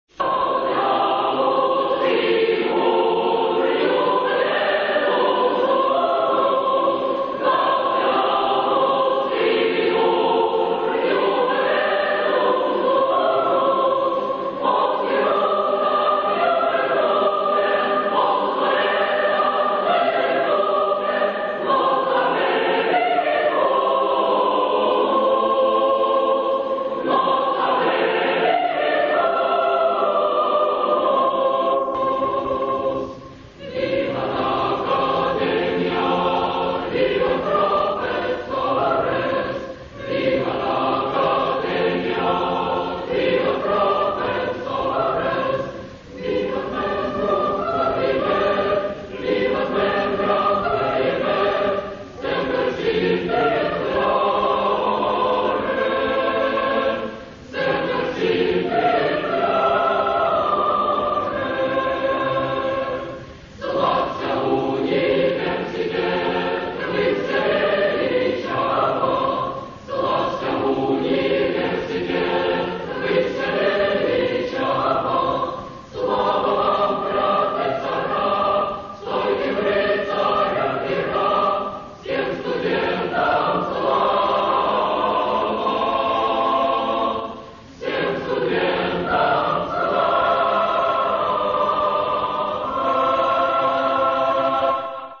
Гимн Студентов-на латинском и немного на русском.